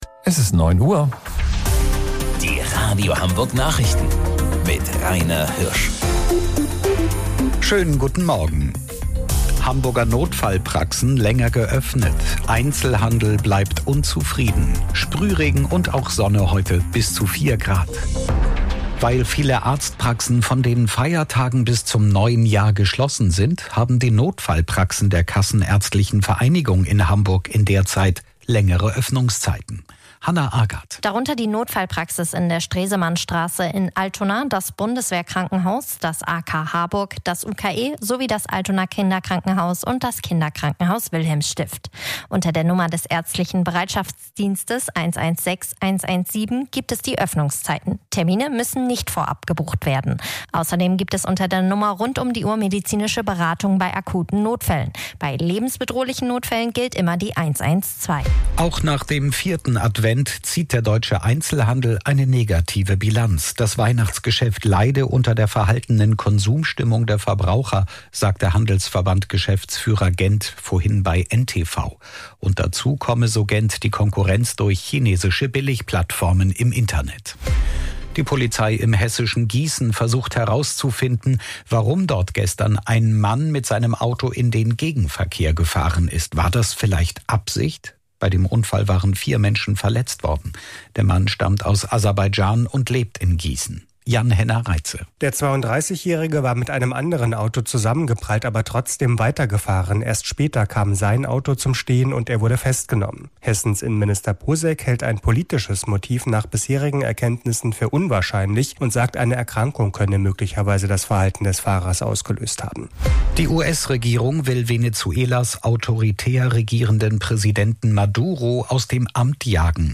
Radio Hamburg Nachrichten vom 23.12.2025 um 09 Uhr